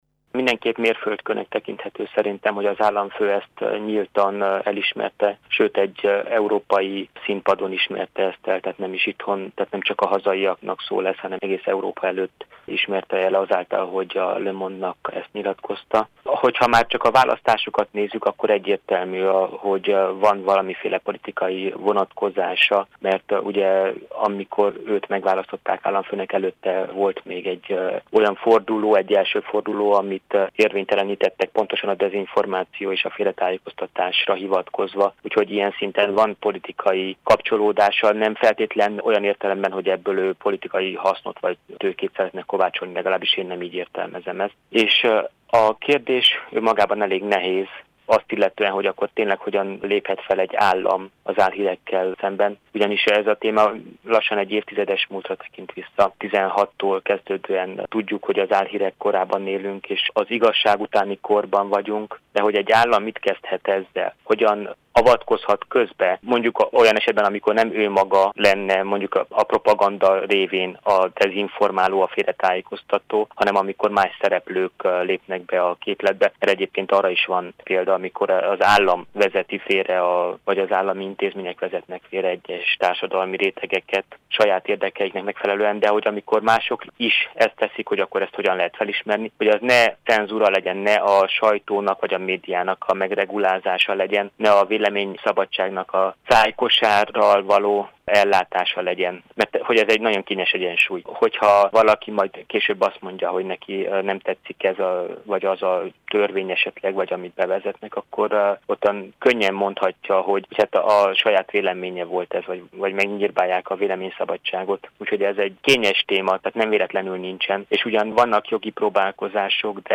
A kijelentésnek már csak azért is van politikai felhangja, mert Nicușor Dan egy, a dezinformáció miatt érvénytelenített választás után indulhatott az elnöki székért – véli a Marosvásárhelyi Rádió által megkérdezett szakértő.